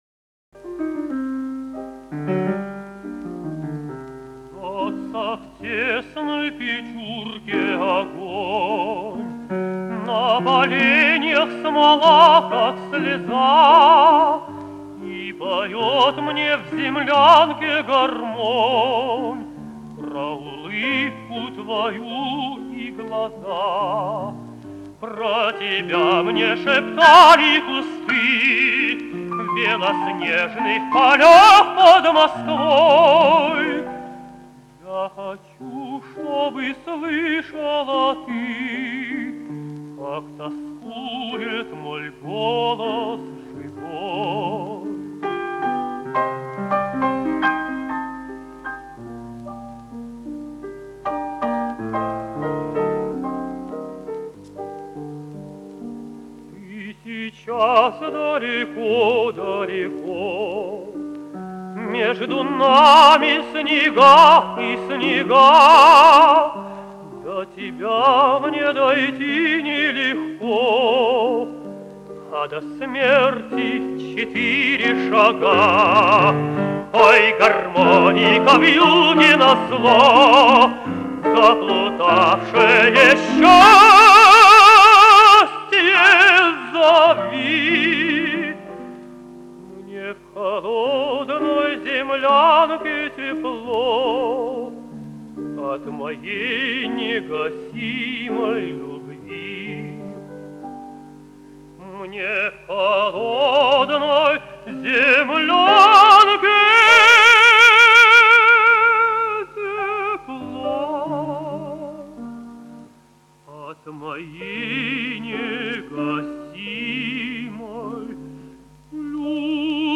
Описание: Исполнено под рояль.
за роялем автор